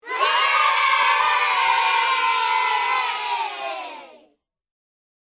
Download Free Meme Sound Effects | Gfx Sounds
Yay-meme-kids-cheering.mp3